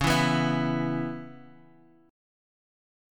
E5/C# chord
E-5th-Csharp-9,7,9,9,x,x.m4a